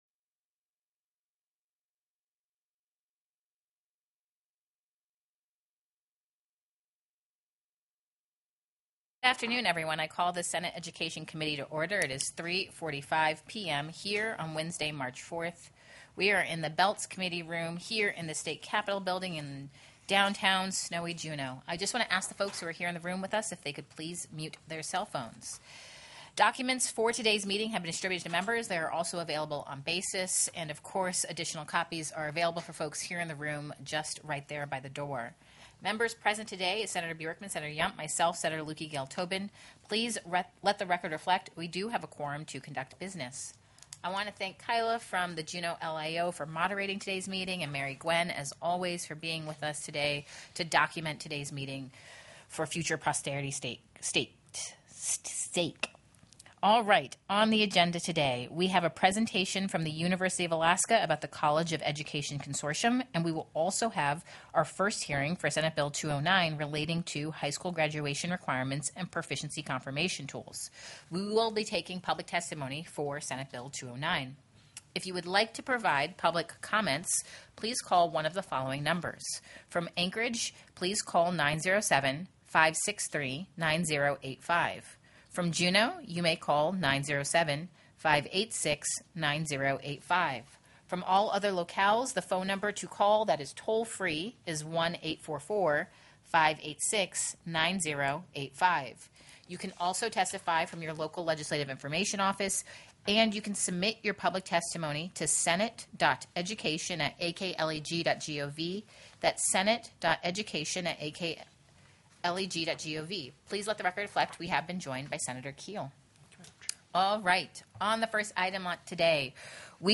The audio recordings are captured by our records offices as the official record of the meeting and will have more accurate timestamps.
TELECONFERENCED
Invited & Public Testimony